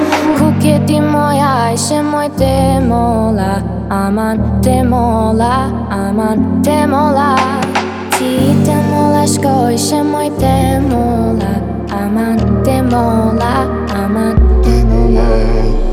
• Качество: 320, Stereo
поп
deep house
красивый женский голос